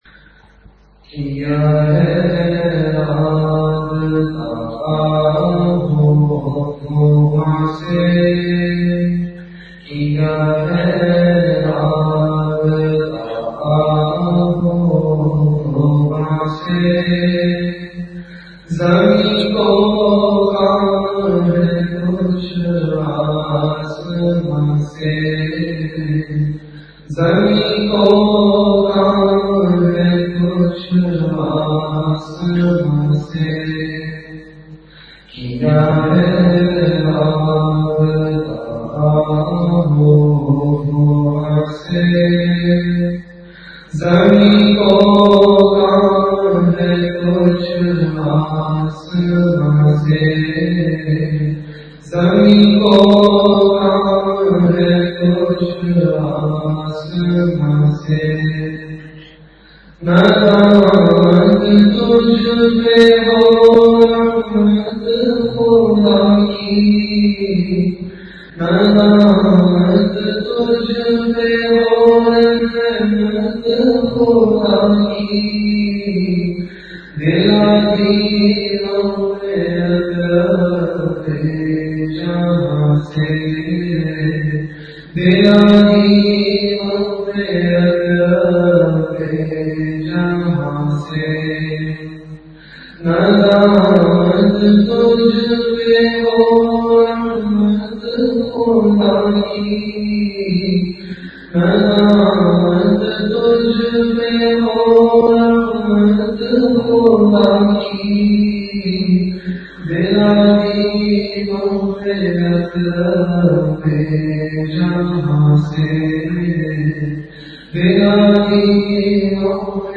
Delivered at Home.
After Isha Prayer